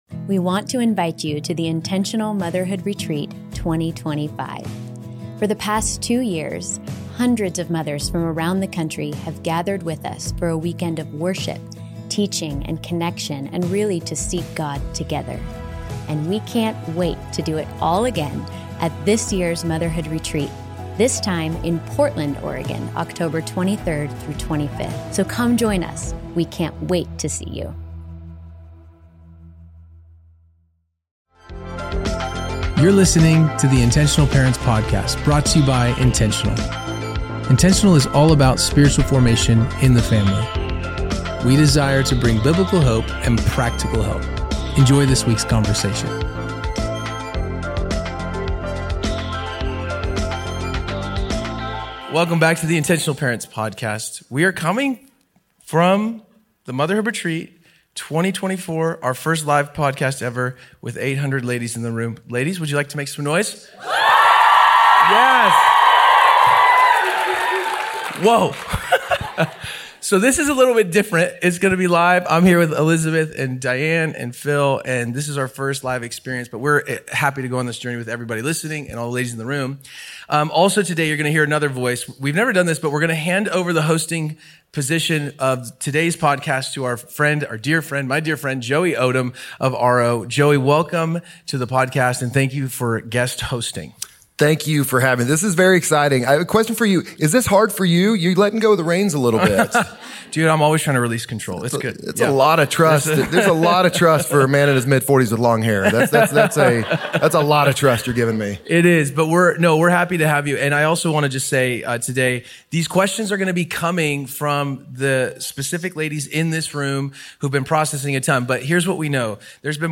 You are in for a special treat; we did our first-ever live Q+R recording from the Motherhood retreat! We had hundreds of women send in questions from the weekend that touch on many deep topics like giving care before criticism, how to encourage your husband, is anything off-limits when you are lamenting with God, how to cut the deadly loop of envy, how to offer help to your friends in a way they can say yes and so much more.